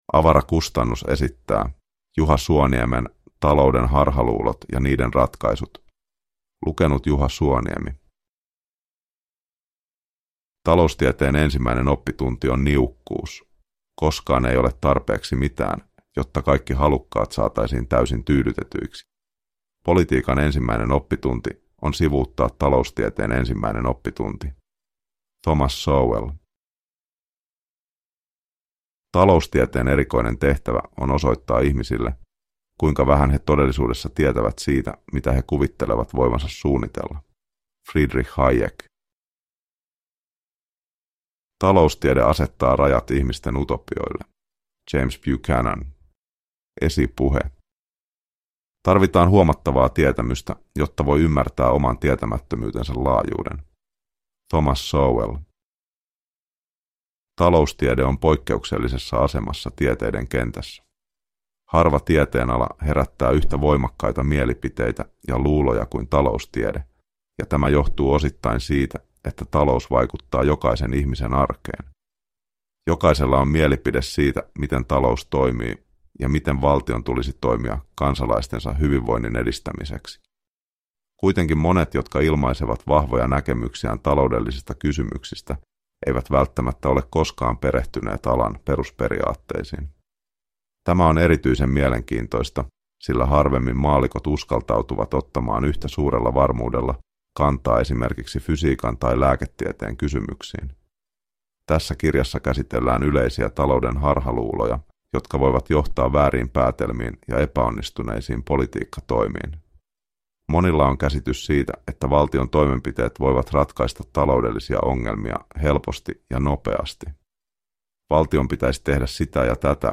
Talouden harhaluulot ja niiden ratkaisut – Ljudbok